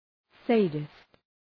Προφορά
{‘seıdıst}
sadist.mp3